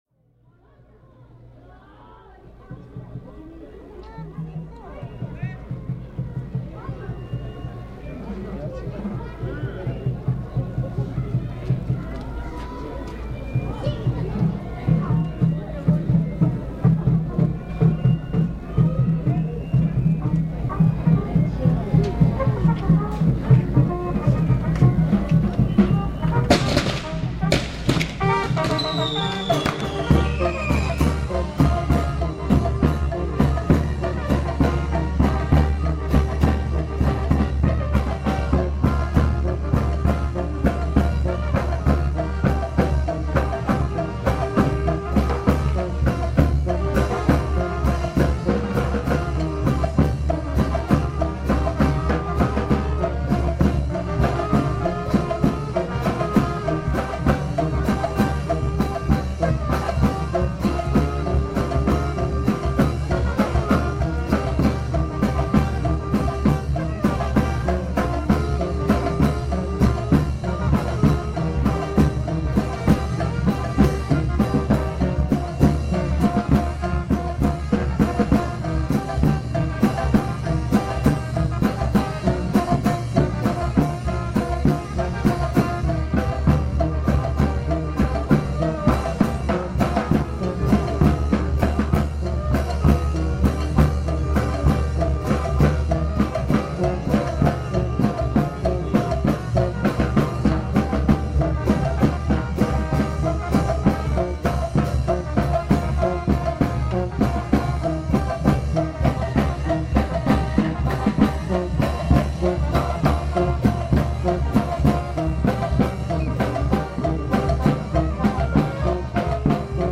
Música en San Andrés Larrainzar
Despues de más de cinco decadas de que mi abuela ya no vive ahí, llegue a San Andres Larrainzar, donde fui testigo de esa tradición que tanto me había contado, ahora era realizada por jóvenes indígenas que encabezan una procesión hacia la iglesia, llevaban la imagen de la Virgen, flores, incienso y tras ellas iban músicos que entonaban este audio que hoy les comparto.
Lugar: San Andres Larrainzar, Chiapas; Mexico.
Equipo: Grabadora Sony ICD-UX80 Stereo, Micrófono de construcción casera (más info)